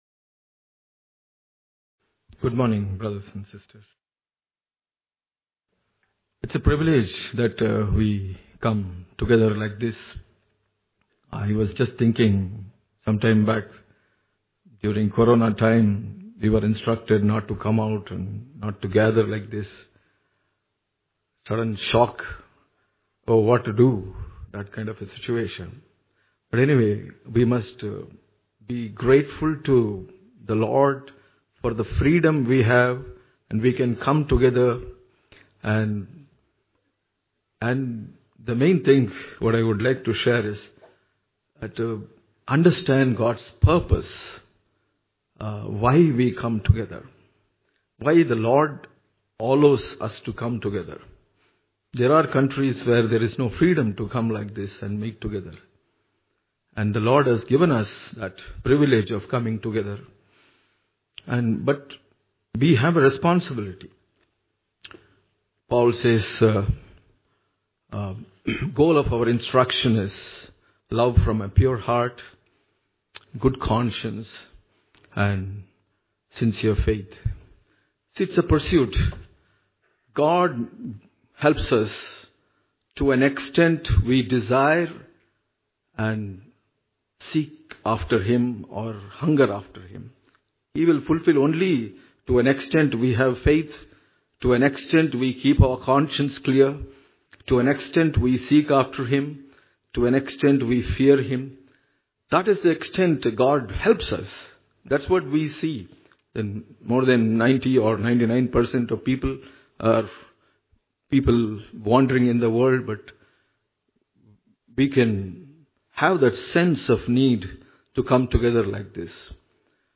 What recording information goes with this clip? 16th November 2025 - CFC Bangalore Sunday Church Service